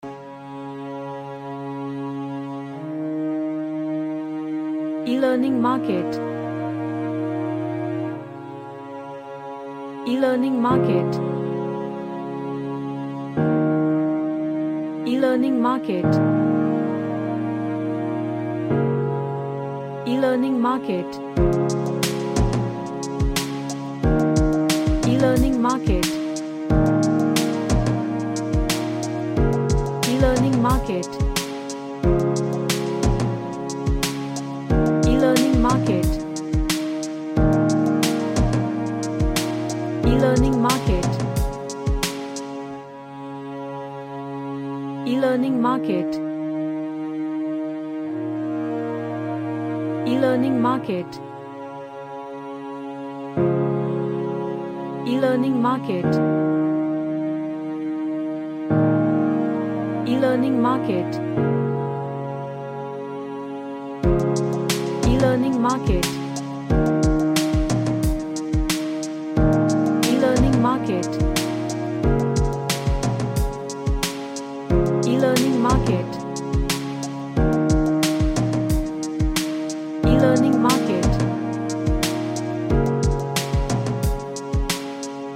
A string featured track with emotional touch
Emotional